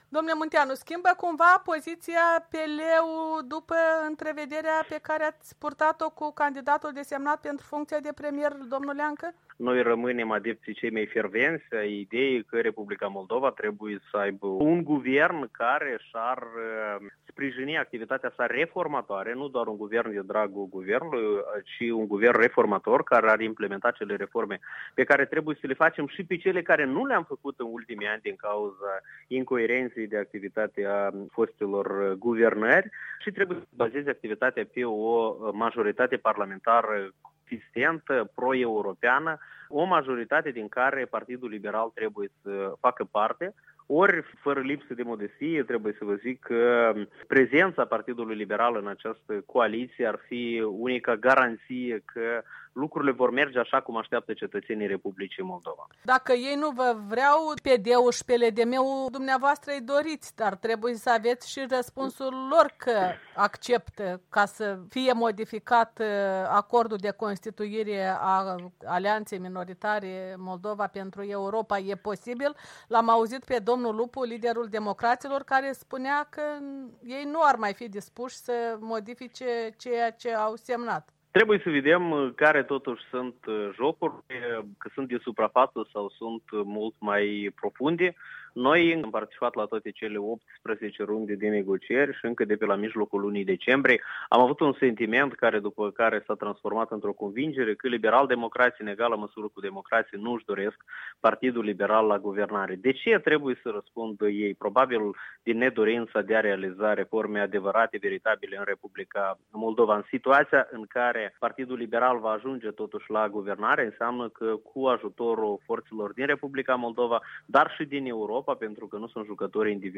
În dialog cu fruntaşul Partidului Liberal Valeriu Munteanu